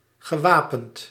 Ääntäminen
US : IPA : /ɑɹmd/ UK : IPA : /ɑːmd/